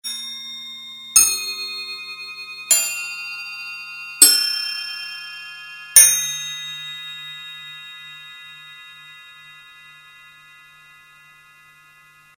Jeu de crotales Zildjian octave aiguë
*** Accord en 442 ***
• 1 octave Do7 - Do8 (13 notes)